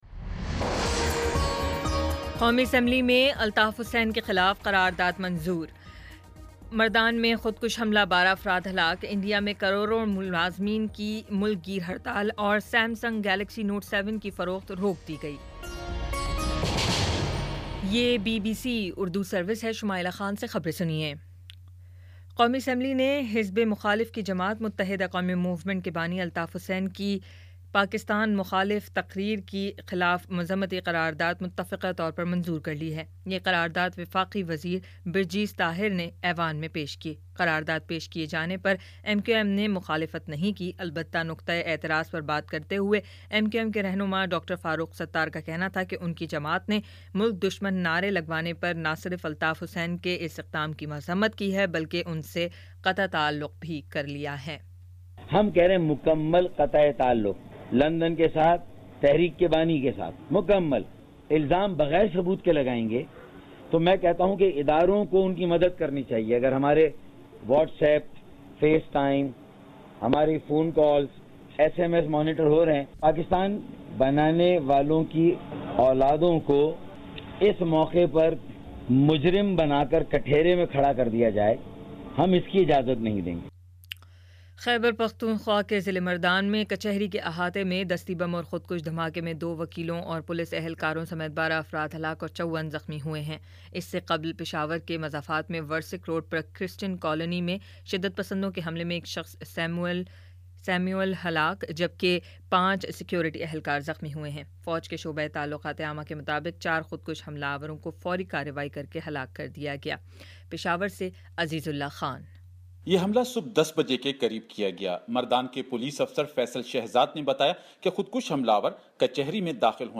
ستمبر 02 : شام پانچ بجے کا نیوز بُلیٹن